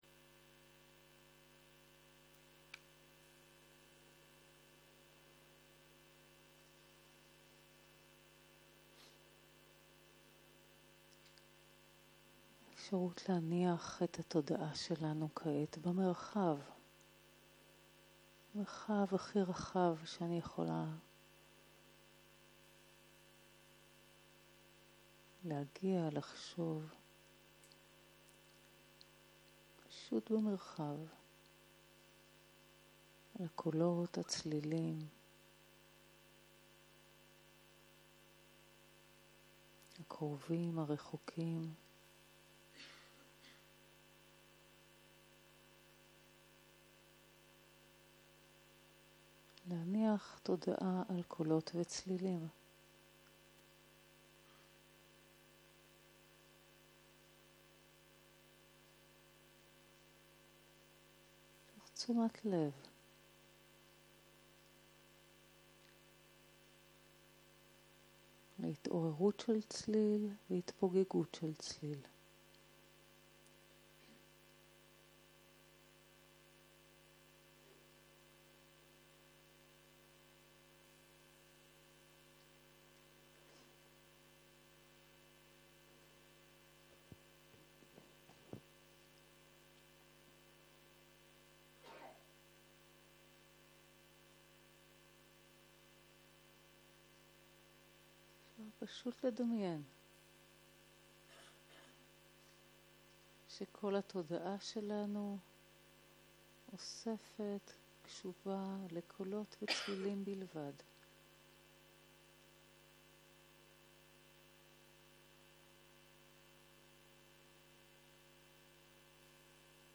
יום 4 - בוקר - מדיטציה מונחית - הקלטה 7